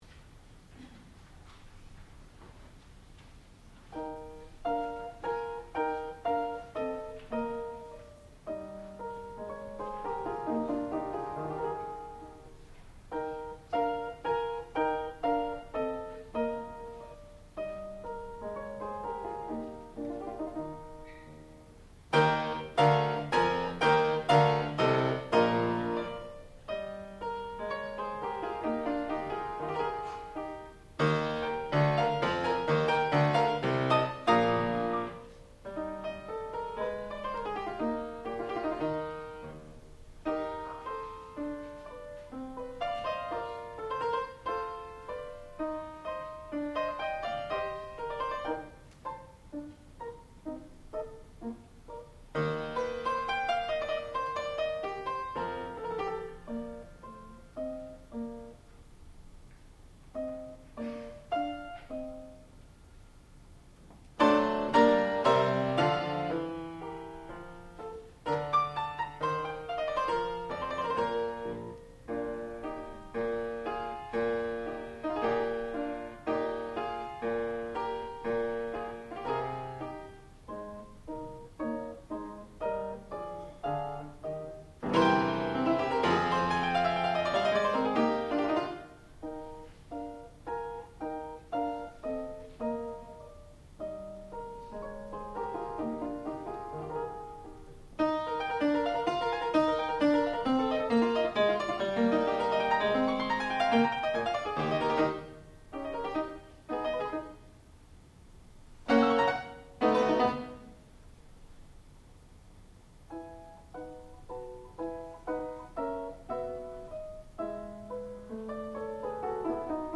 sound recording-musical
classical music
fortepiano